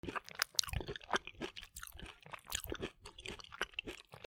みかんを食べる
『クチャクチャ』